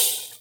Hihat_01.wav